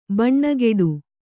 ♪ baṇṇageḍu